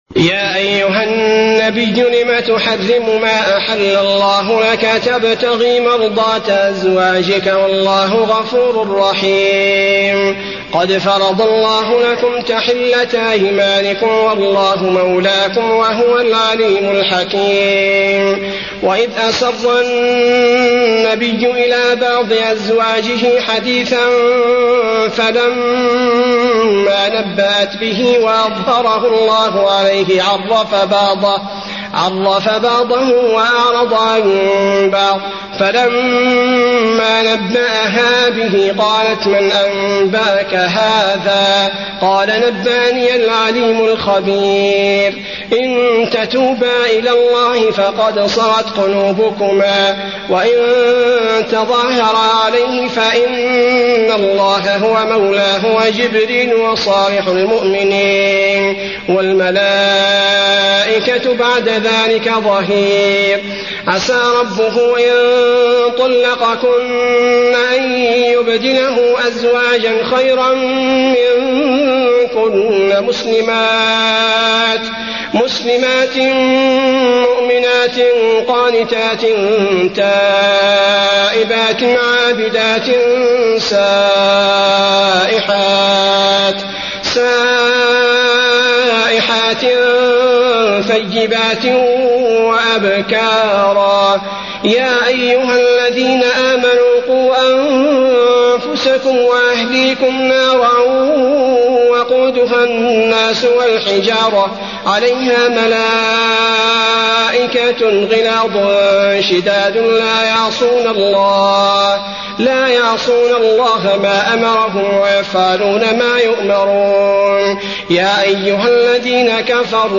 المكان: المسجد النبوي التحريم